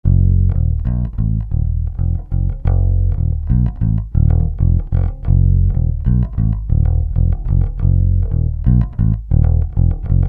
Bass 08.wav